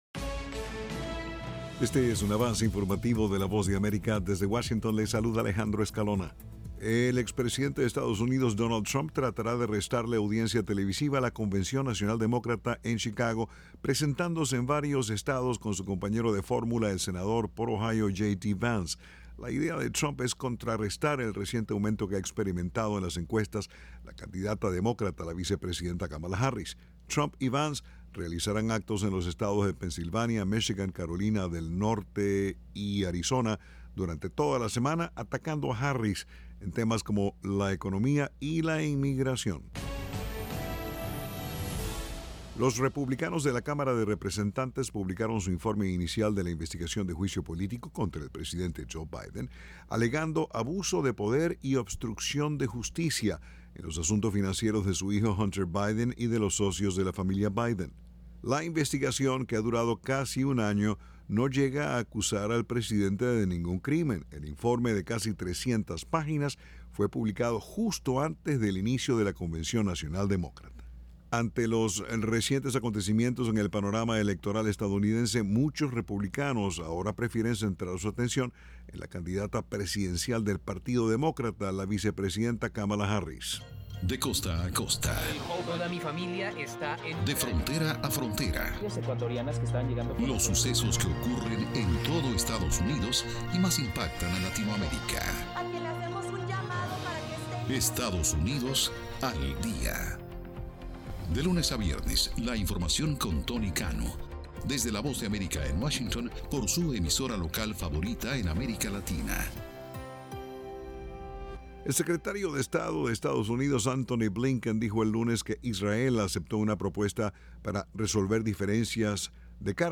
Este es un avance informativo presentado por la Voz de America en Washington.